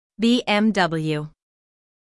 🇺🇸 American:
Syllables: B · M · W
bmw-us.mp3